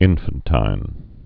(ĭnfən-tīn, -tĭn)